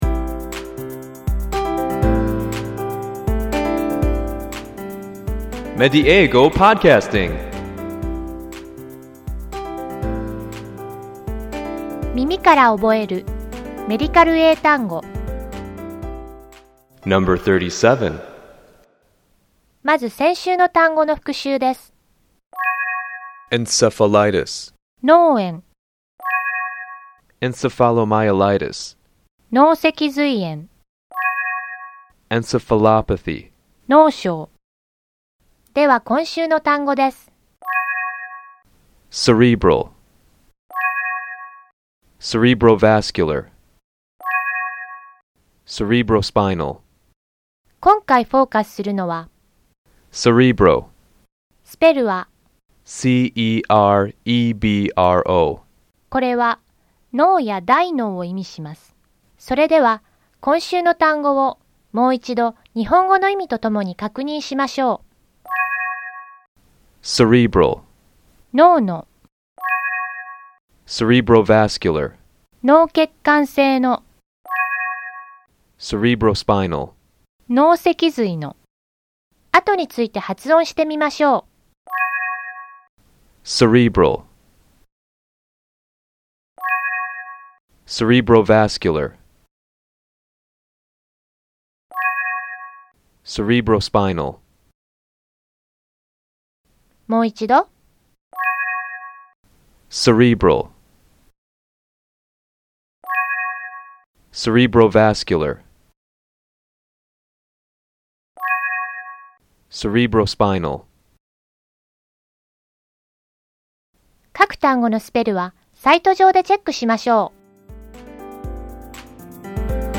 ネイティブの発音を聞いて，何度も声に出して覚えましょう。